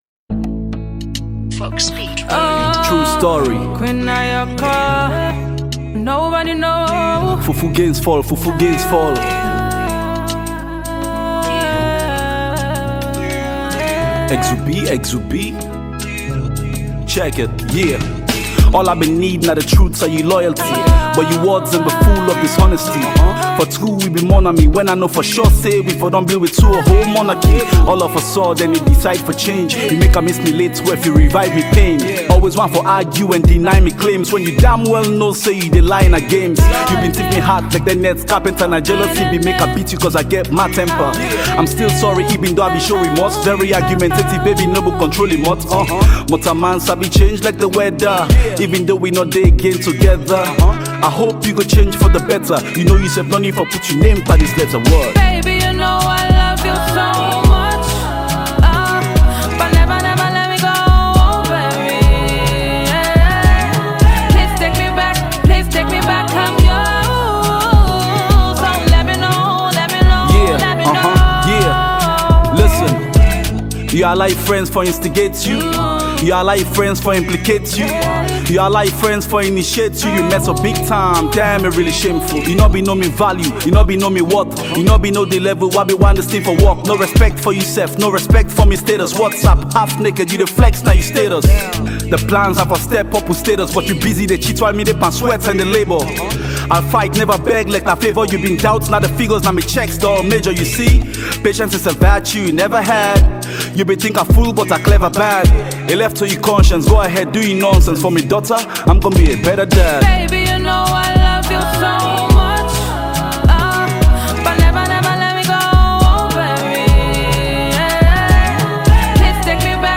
combine hip-hop, afrobeat, and other musical styles